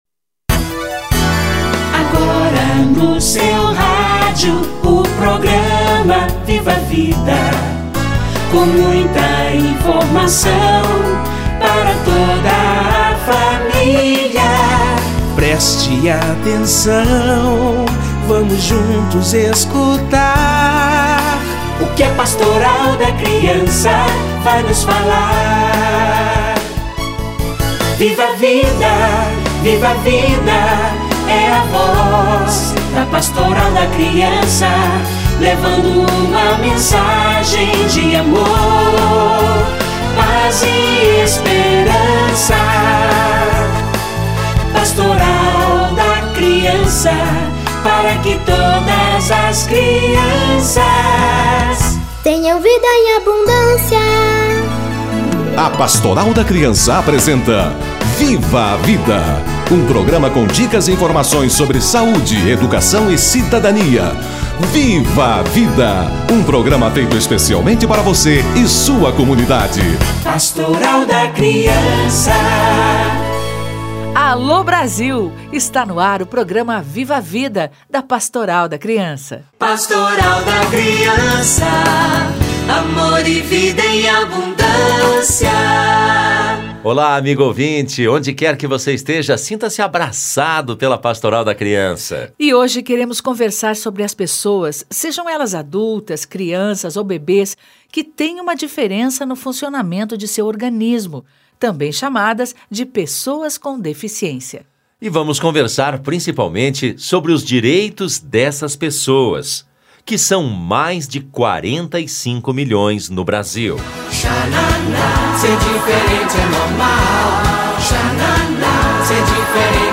Crianças com diferenças no funcionamento de seu organismo - Entrevista